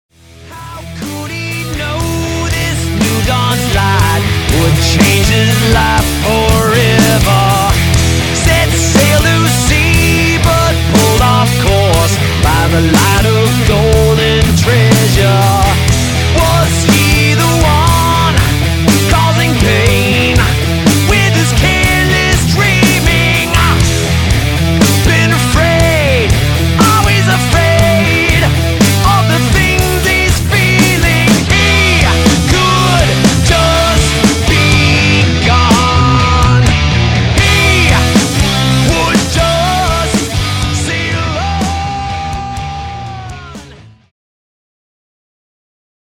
Heavy Metal
17_metal_heavy_metal__fragment.mp3